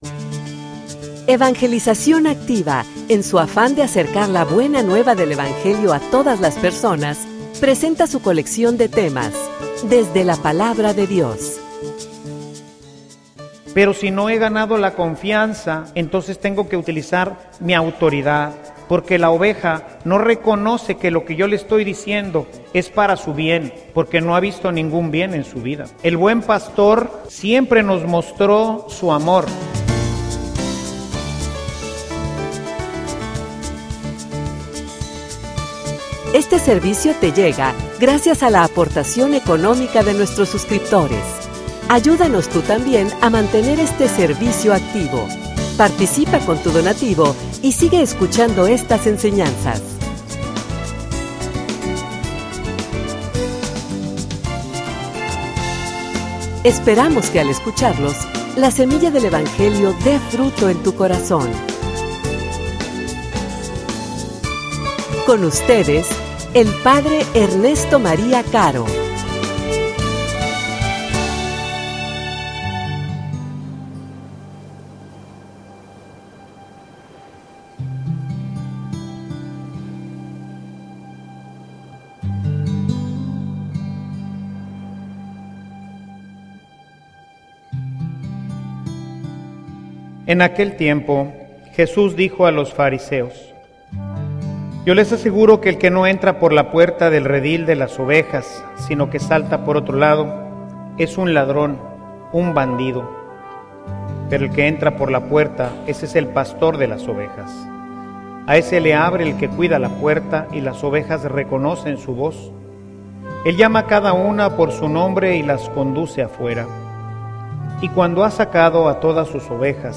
homilia_Reconoces_la_voz_del_Pastor.mp3